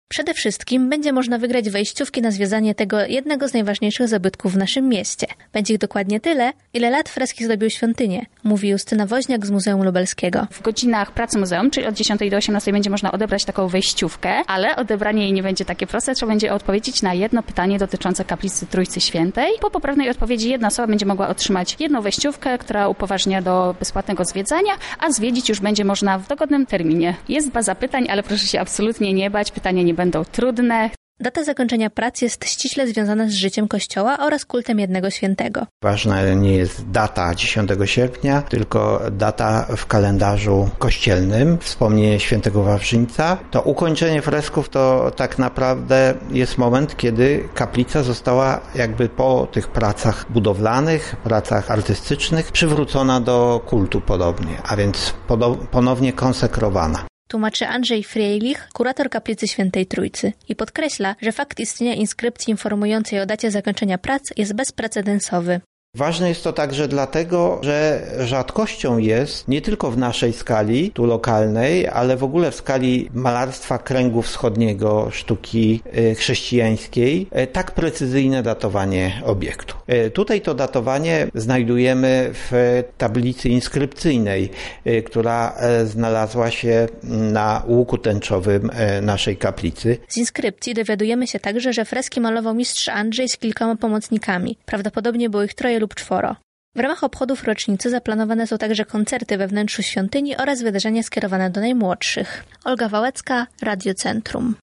Więcej szczegółów ma dla was nasza reporterka: